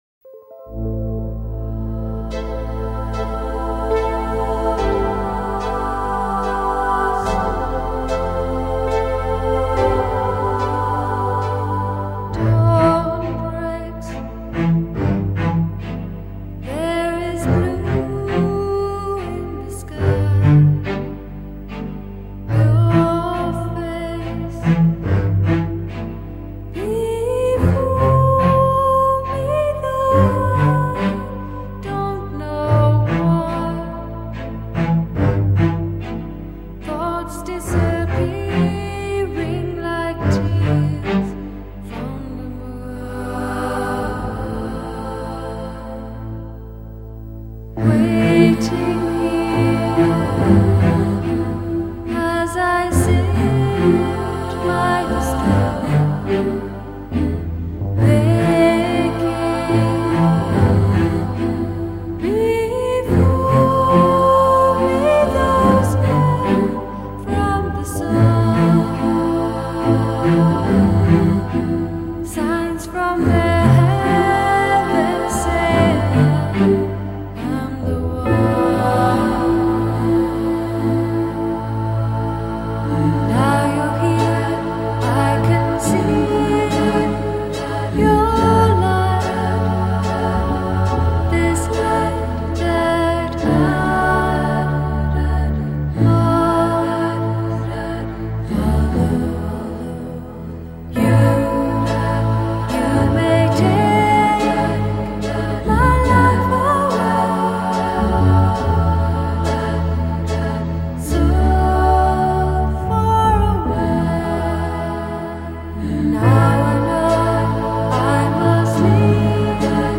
Celtic new age